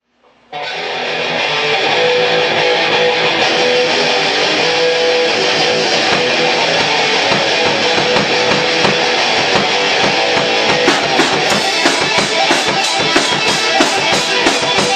Metal, Rock